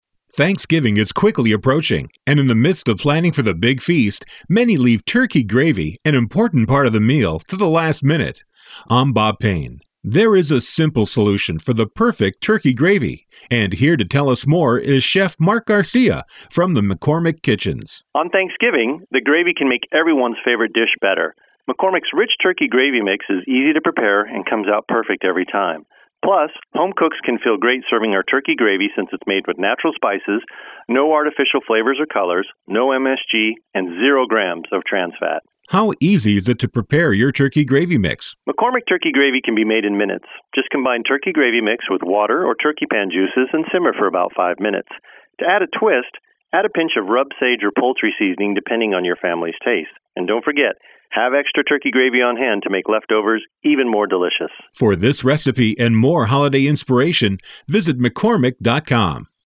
November 12, 2012Posted in: Audio News Release